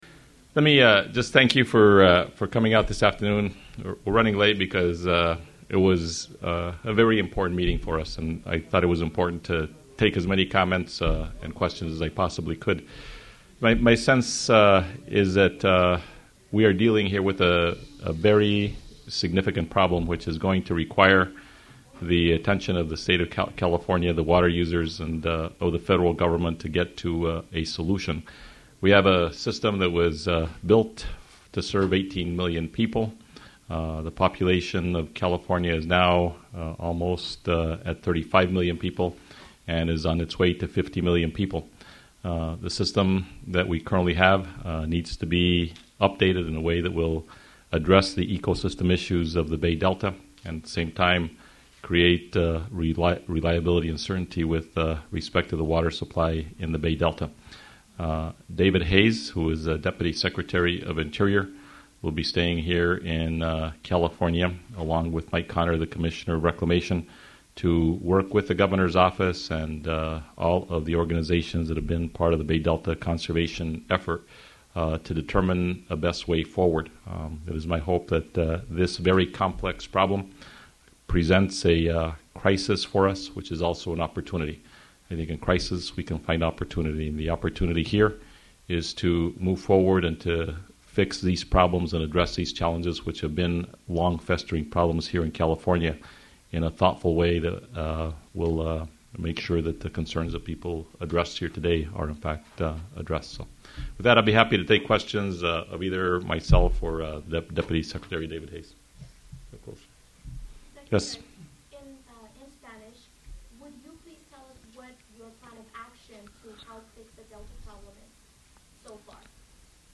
This is the audio (15:15 minute) from Secretary of the Interior Ken Salazar’s press conference, following the Town Hall meeting in Fresno on Sunday, June 28. Also speaking is Deputy Secretary David Hayes.
press_conference.mp3